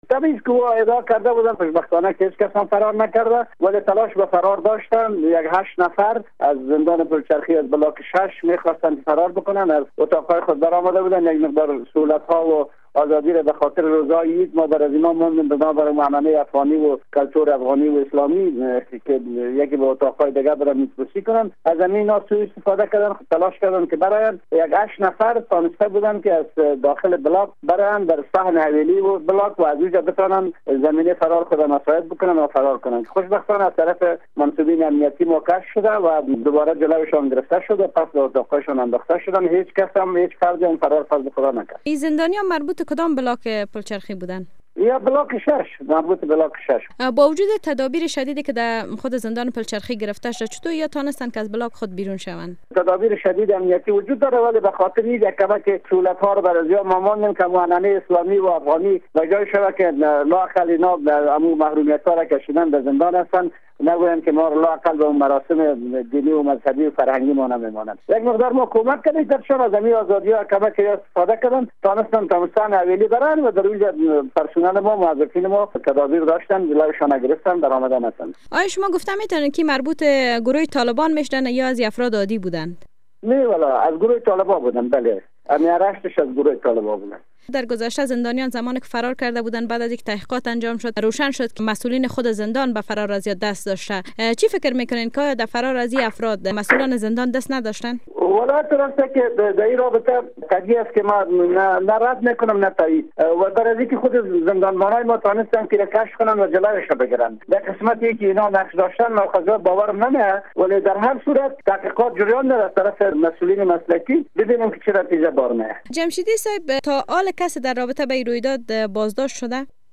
مصاحبه با دگرجنرال امیر محمد جمشید در مورد فرار زندانیان از پلچرخی